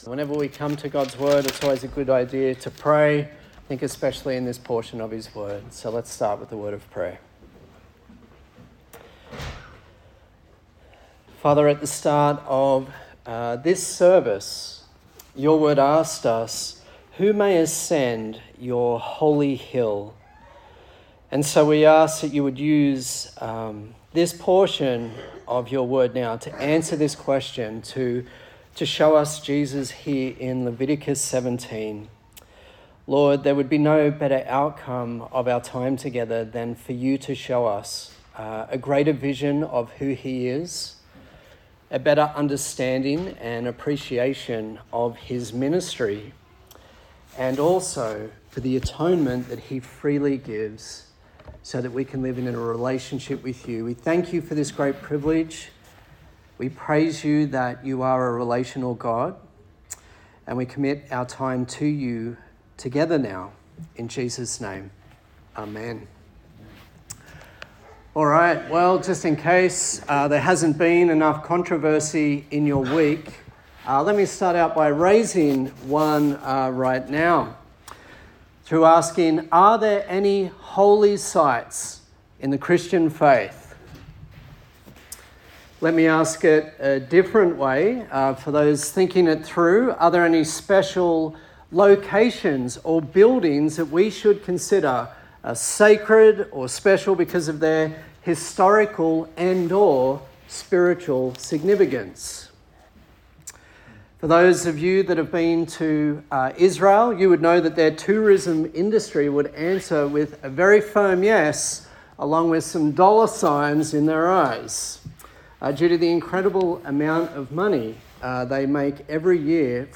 Service Type: Sunday Service